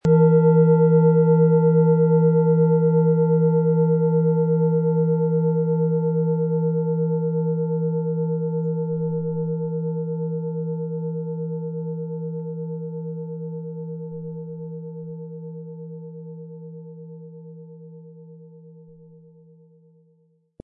Hopi Herzton
• Tiefster Ton: Mond
Wie klingt diese tibetische Klangschale mit dem Planetenton Hopi-Herzton?
Durch die traditionsreiche Fertigung hat die Schale vielmehr diesen kraftvollen Ton und das tiefe, innere Berühren der traditionellen Handarbeit
SchalenformOrissa
MaterialBronze